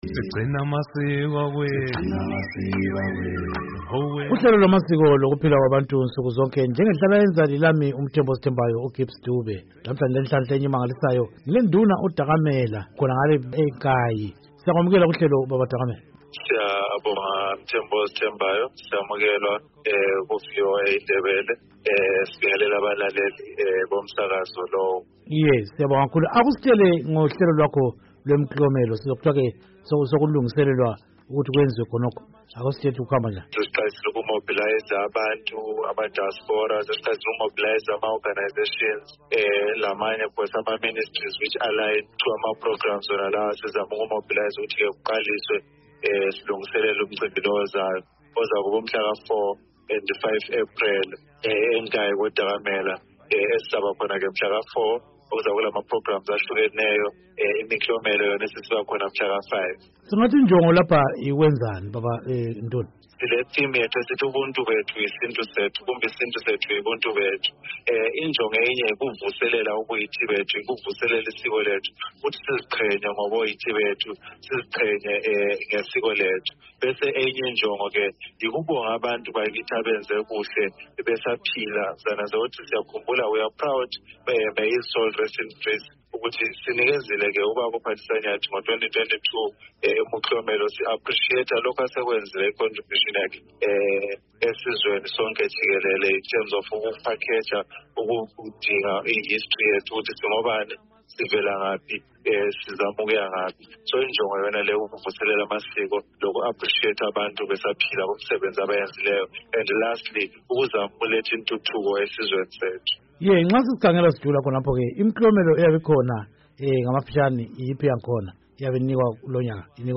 Ezamasiko: Sixoxa lenduna uDakamela ngohlelo lokuvuselela amasiko.